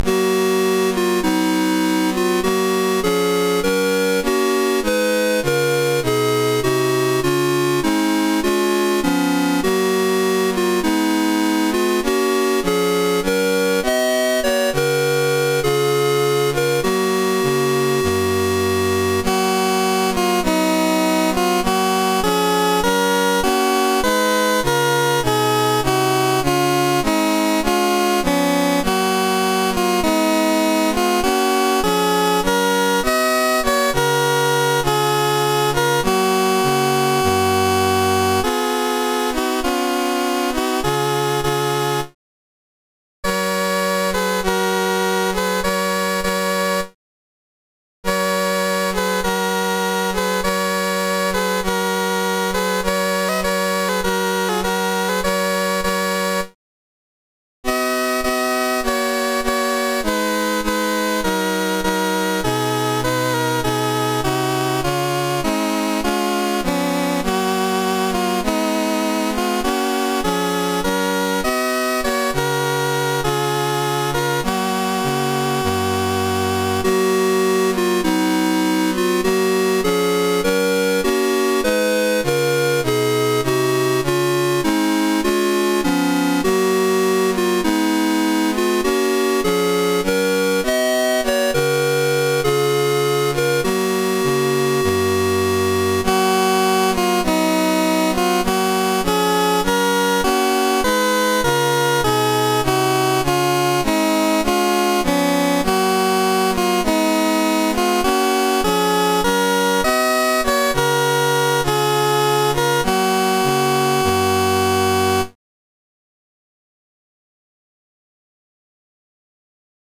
< prev next > Commodore SID Music File
1 channel